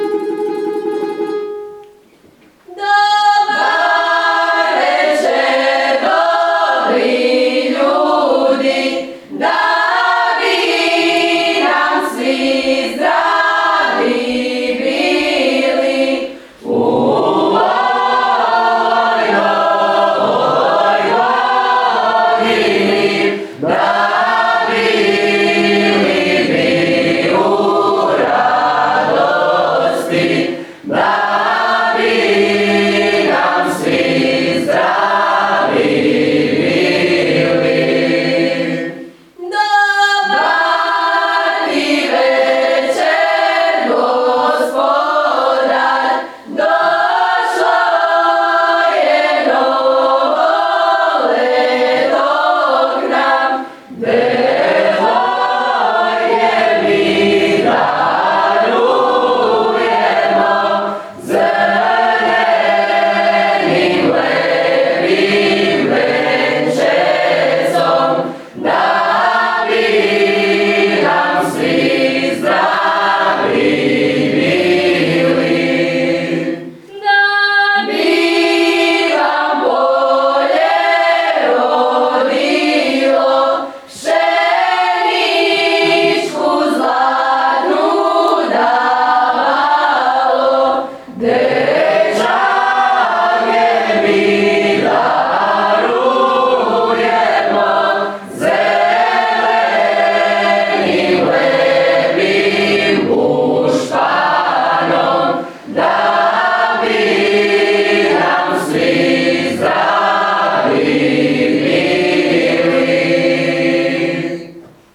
U ponedjeljak, 30. prosinca u Kulturno-povijesnom centru SMŽ u Sisku održana je završna konferencija projekta koja je započela pjesmom članova Ansambla